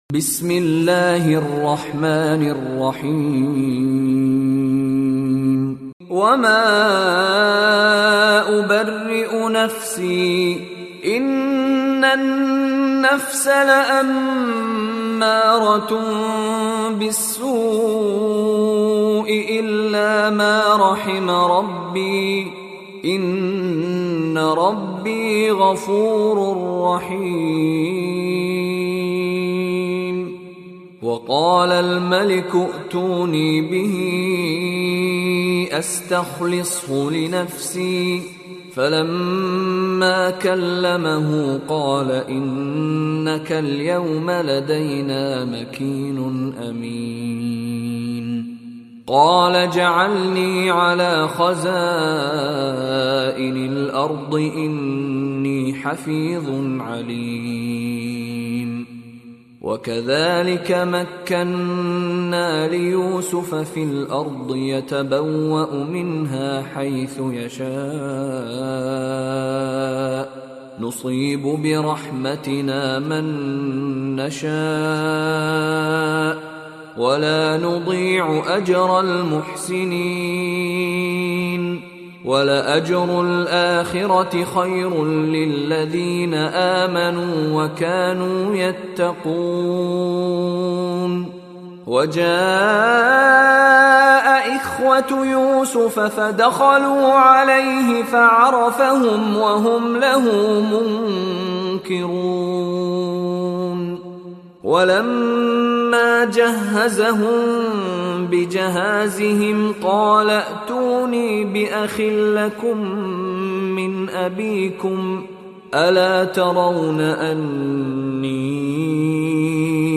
دانلود ترتیل جزء سیزدهم قرآن کریم با صدای مشاری بن راشد العفاسی | مدت : 62 دقیقه